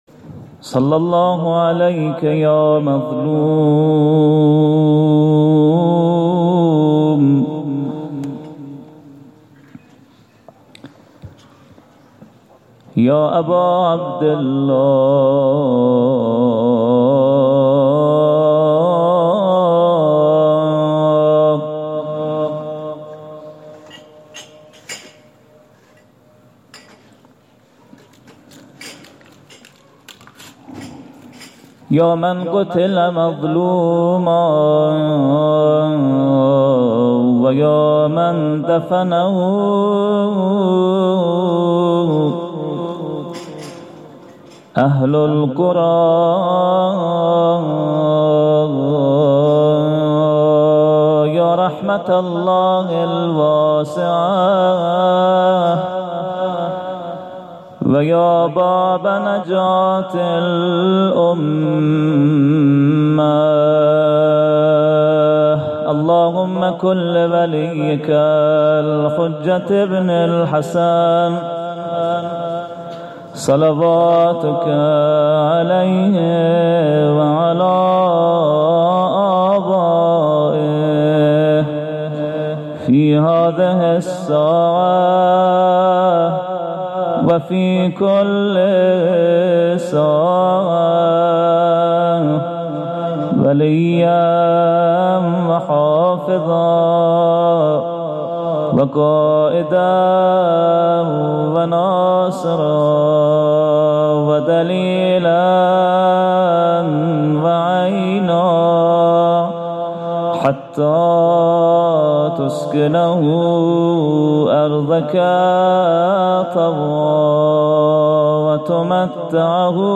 هیأت محبان اهل بیت علیهم السلام چایپاره